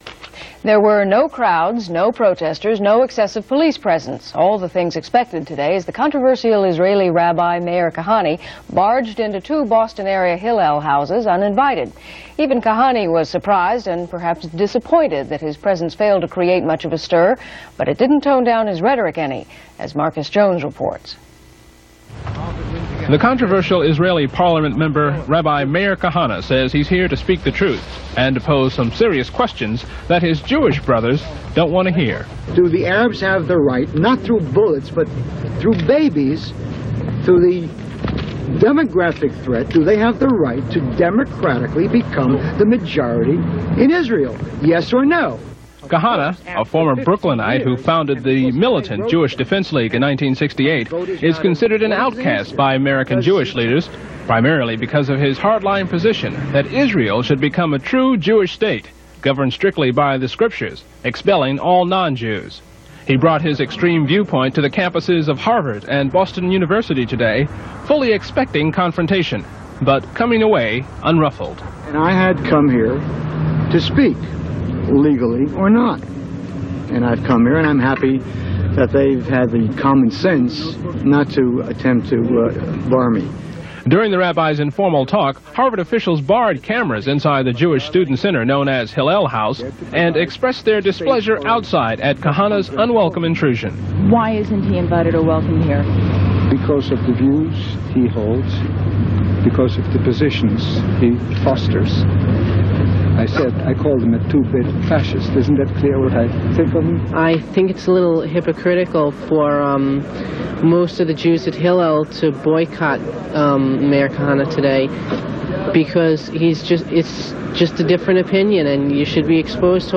RARE- Rabbi Meir Kahane visits Boston to stir the faithful.mp3